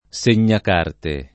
[ S en’n’ak # rte ]